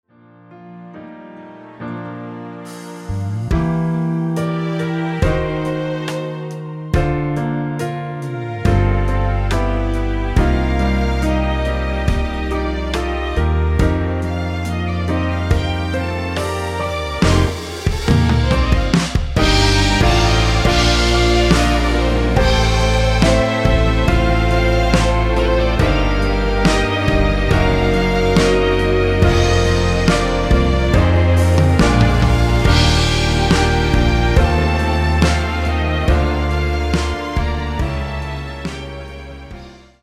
1절앞 + 후렴)MR입니다.
Bb
앞부분30초, 뒷부분30초씩 편집해서 올려 드리고 있습니다.
중간에 음이 끈어지고 다시 나오는 이유는